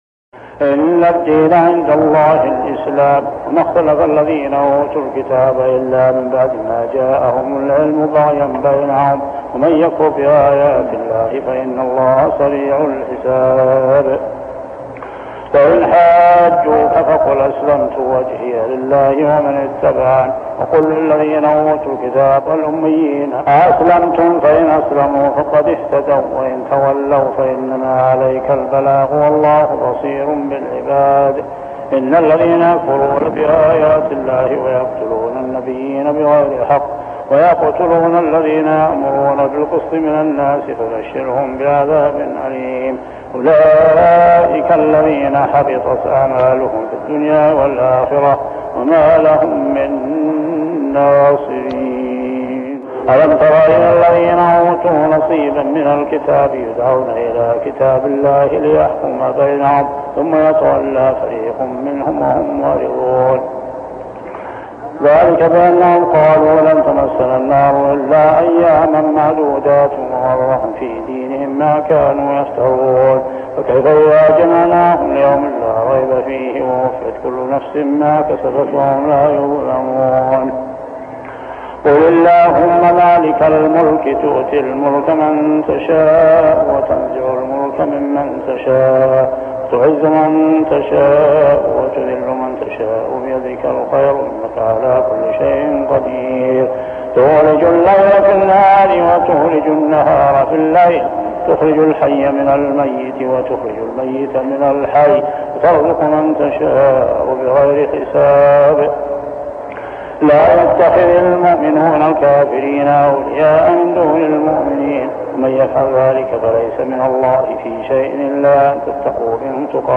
صلاة التراويح ليلة 4-9-1403هـ سورة آل عمران 19-91 | Tarawih prayer Surah Al-Imran > تراويح الحرم المكي عام 1403 🕋 > التراويح - تلاوات الحرمين